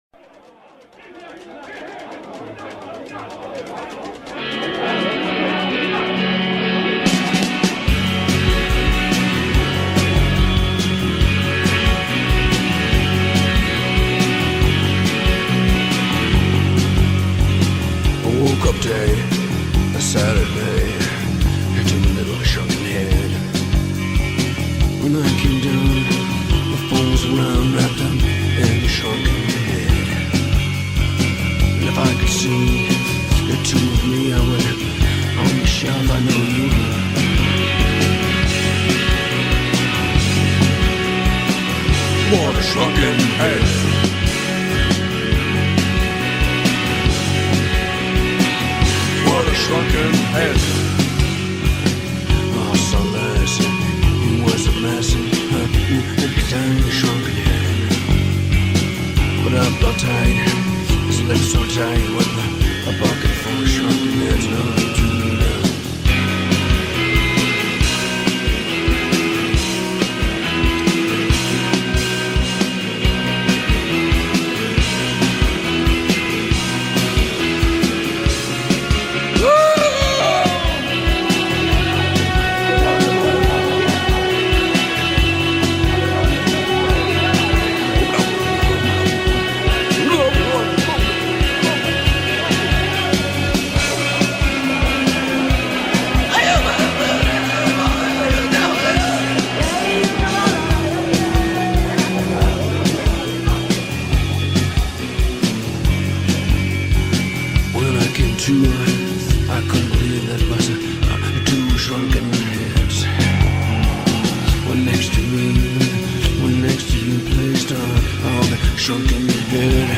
Remastered with liner notes.